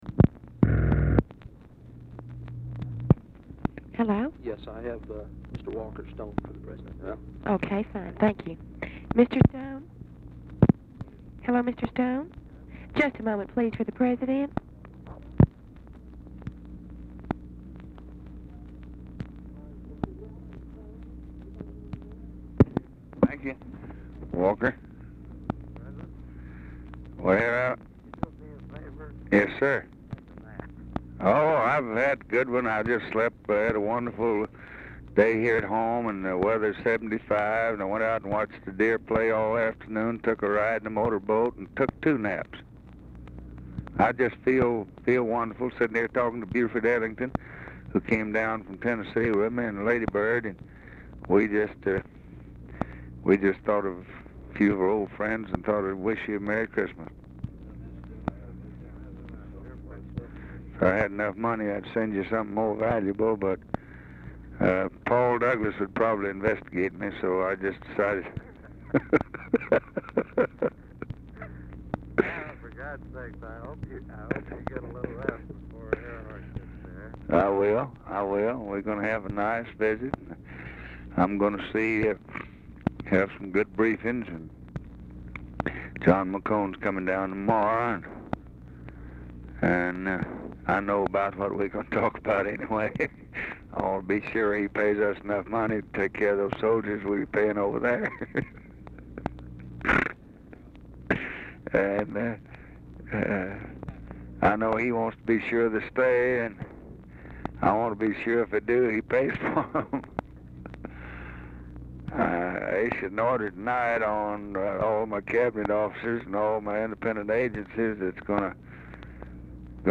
Telephone conversation
Format Dictation belt